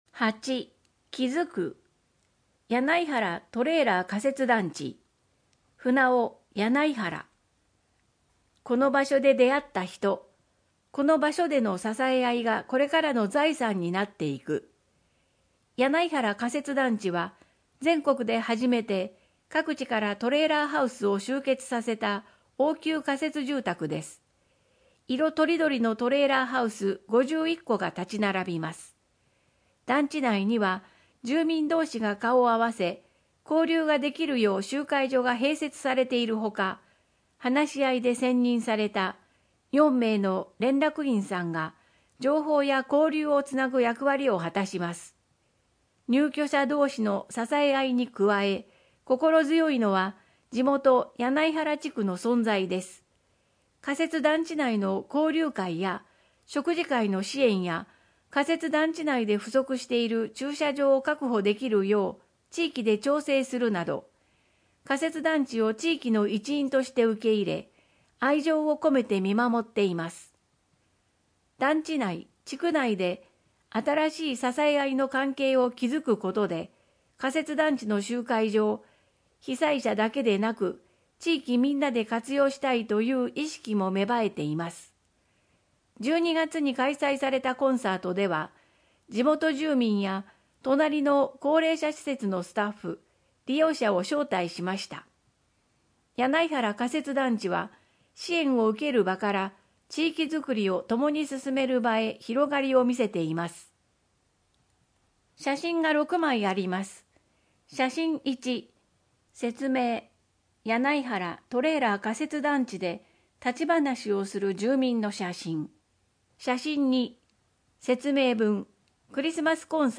豪雨ニモマケズ（音訳版）